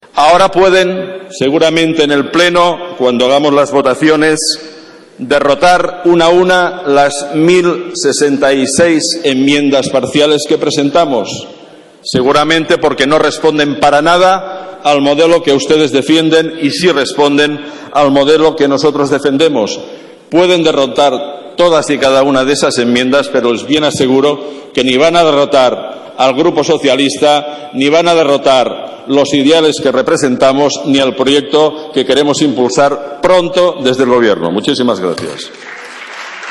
Fragmento de la intervención de Joan Rangel en el debate de presupuestos del 11/11/2013